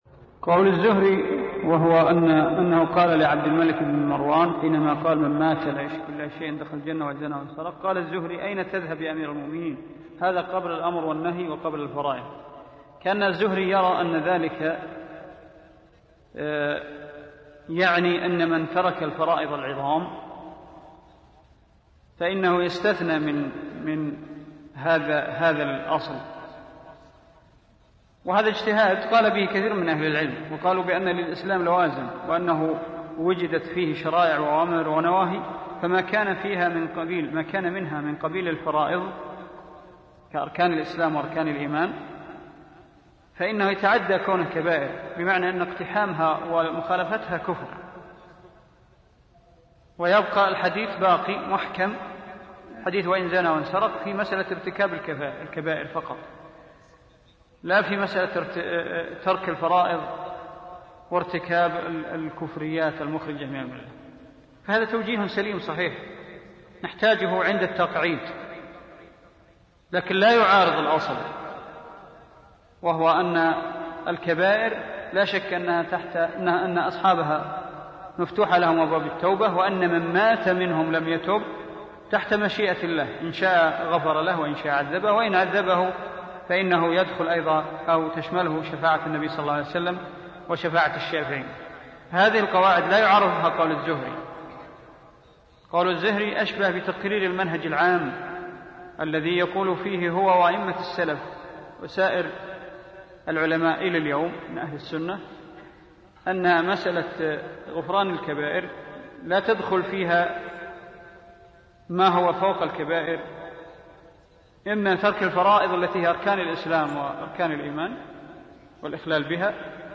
عنوان المادة الدرس (32) شرح أصول إعتقاد أهل السنة والجماعة تاريخ التحميل الأحد 1 يناير 2023 مـ حجم المادة 42.21 ميجا بايت عدد الزيارات 306 زيارة عدد مرات الحفظ 120 مرة إستماع المادة حفظ المادة اضف تعليقك أرسل لصديق